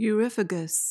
PRONUNCIATION:
(yoo-RIF-uh-guhs)